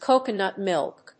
音節cóconut mìlk